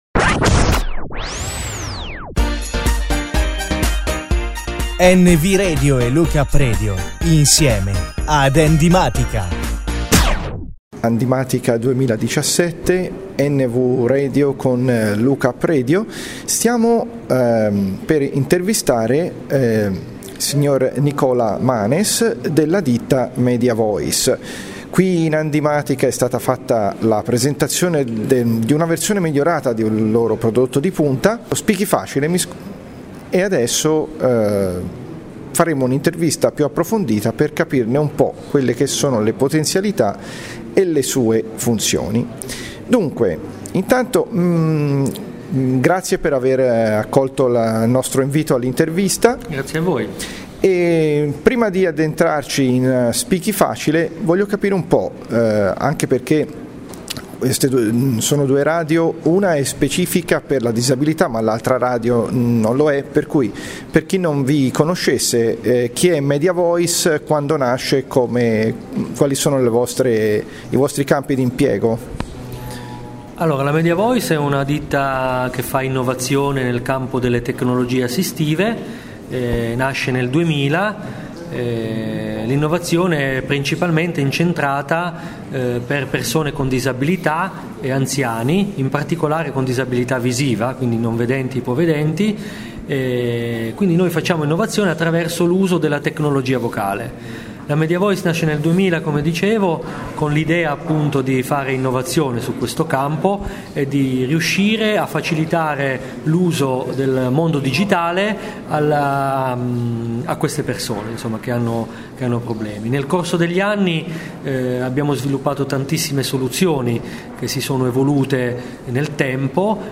In questa intervista sarà possibile ascoltare Speaky Facile in azione ed apprendere le caratteristiche della versione 5.0